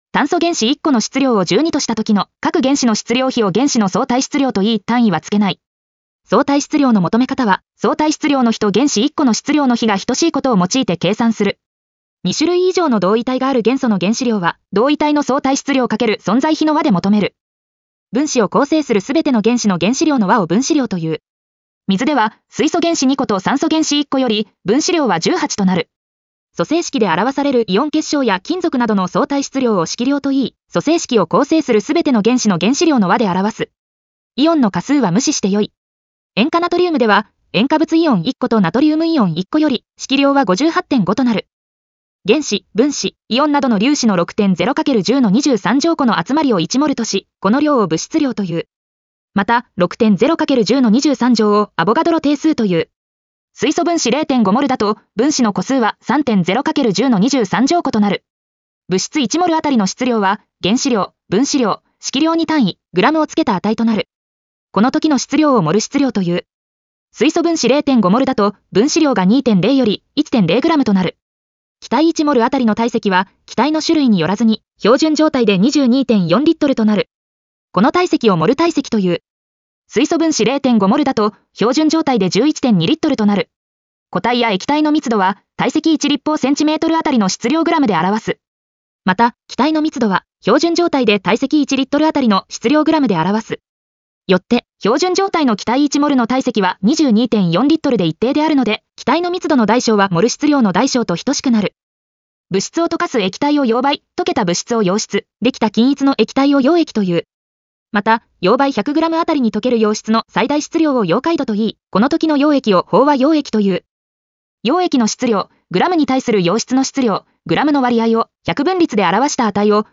ナレーション 音読さん